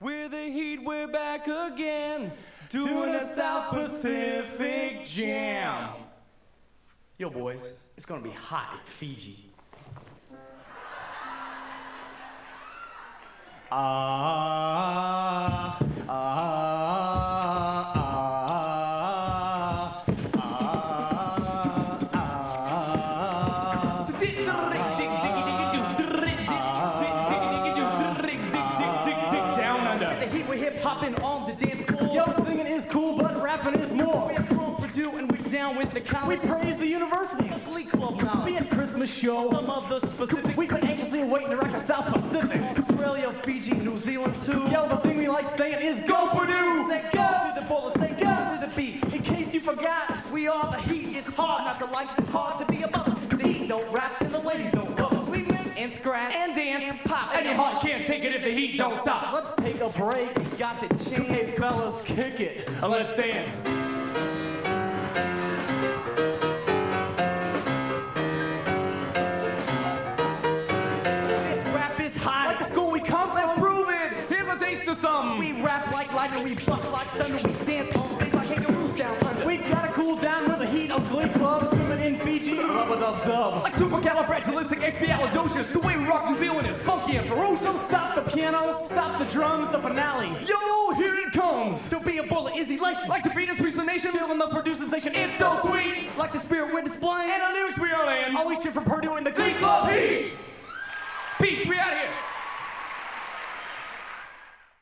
Genre: | Type: End of Season |Specialty